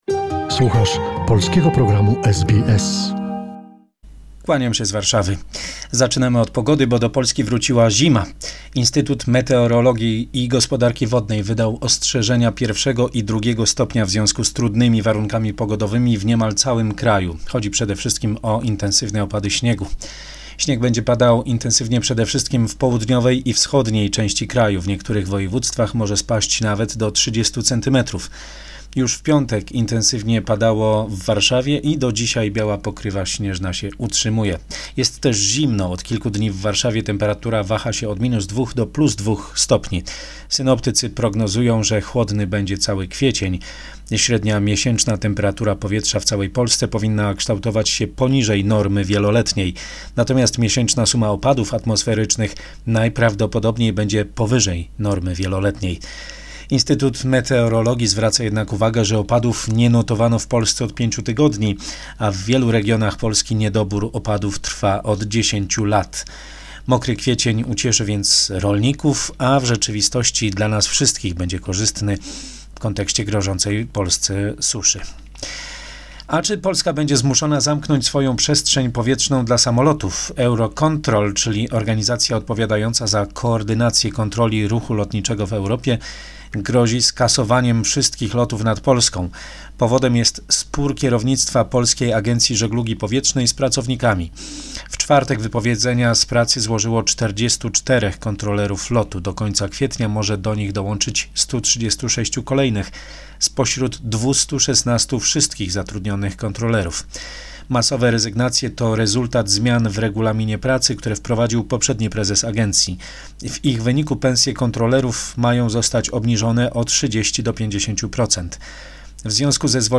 Summary of the important events in Poland. Report